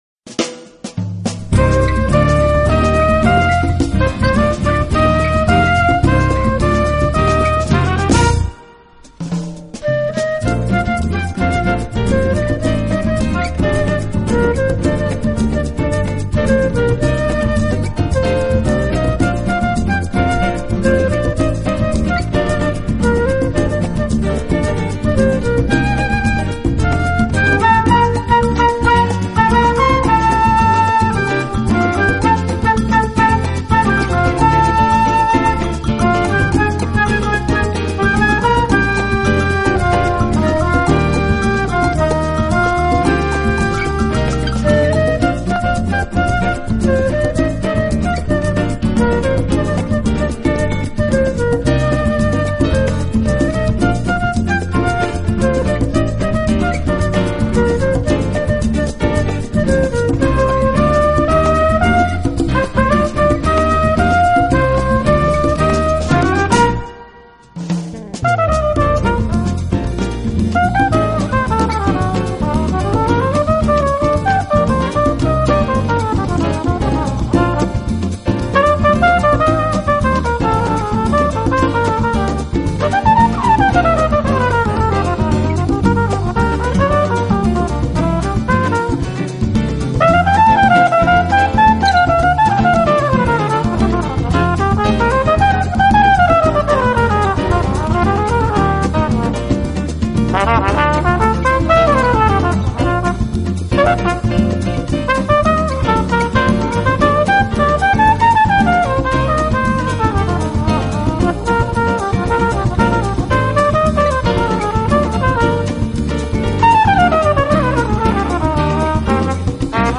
a driving two horn Latin-Jazz group with a commercial sound
guitar / tres
trumpet
sax / flute
bass
congas/percussion